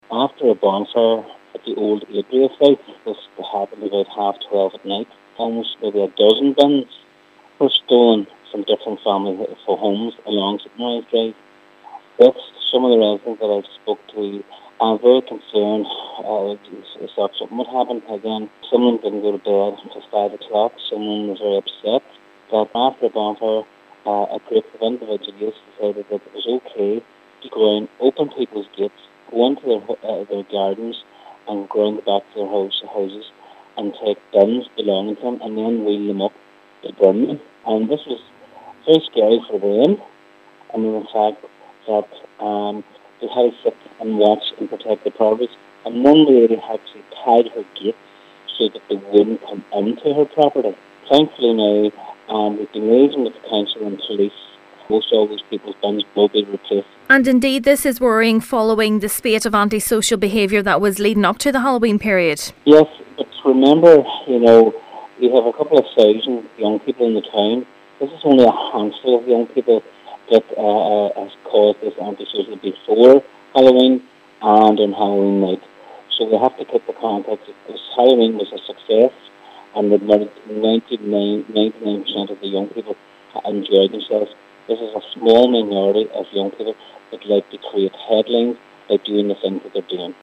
Local Councillor Patsy Kelly has been condemning the incident: